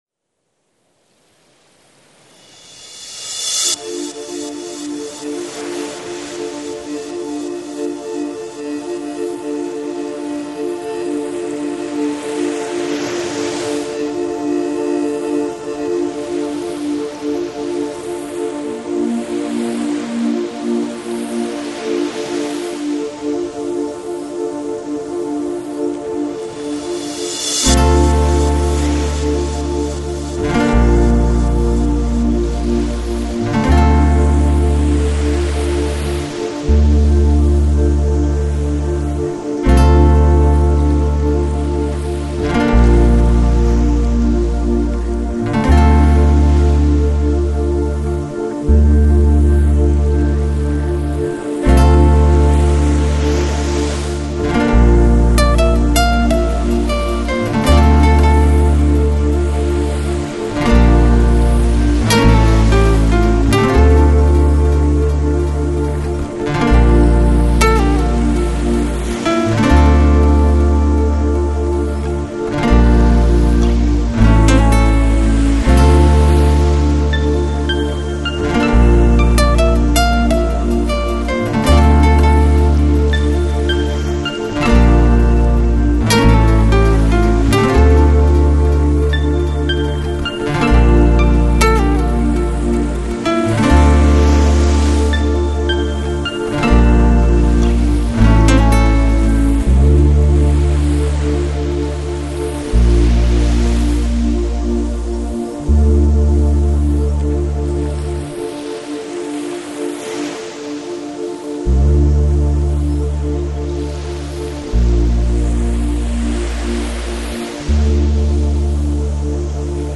Downtempo, Chill Out, Lounge